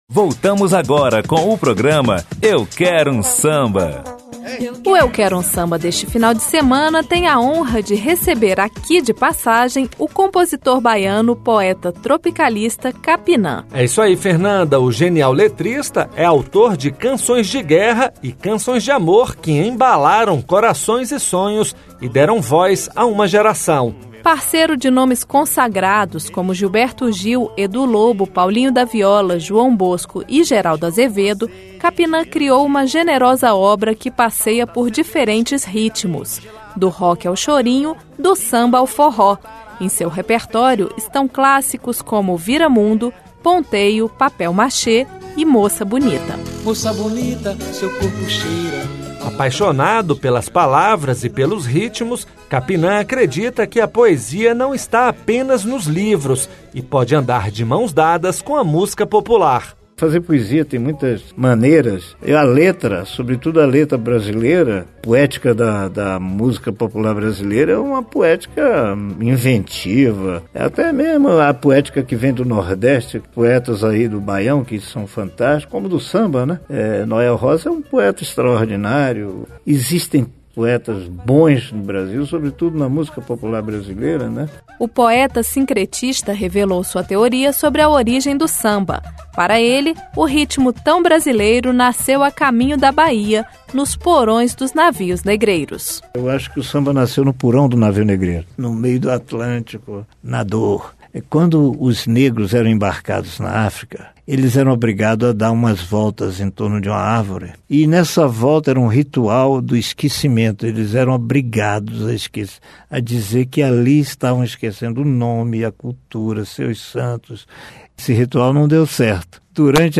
Autor de clássicos da MPB, como Ponteio, Papel machê, Moça bonita e Soy loco por ti América, o poeta, que também é médico, conta aos ouvintes um pouco de sua trajetória e relembra histórias vividas na época da ditadura militar e nos memoráveis festivais de música brasileira.